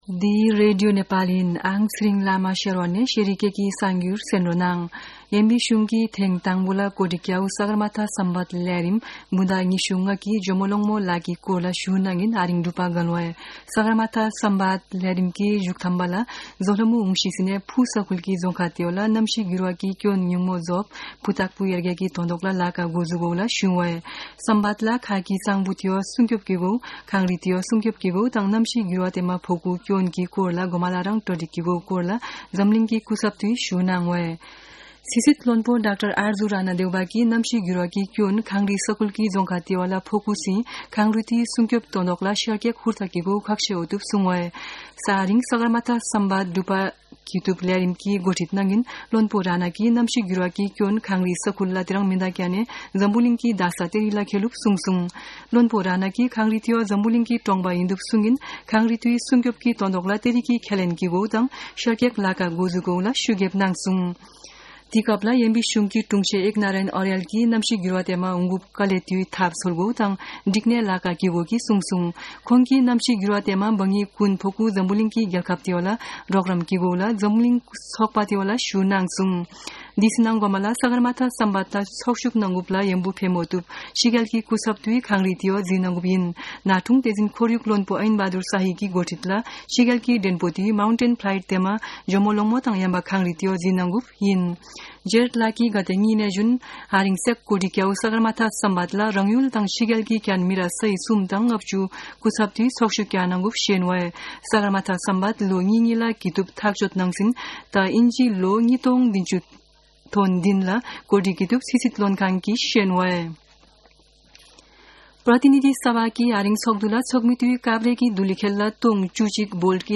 शेर्पा भाषाको समाचार : ४ जेठ , २०८२
sherpa-news.mp3